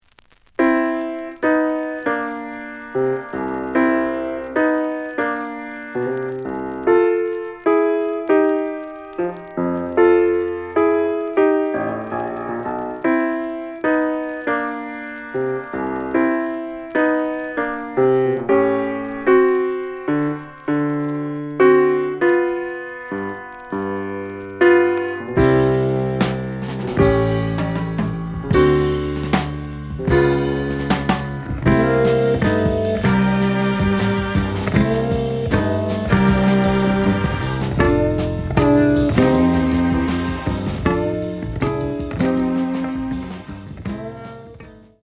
The audio sample is recorded from the actual item.
●Genre: Jazz Funk / Soul Jazz
Slight noise on both sides.